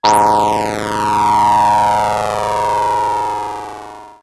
Machine26.wav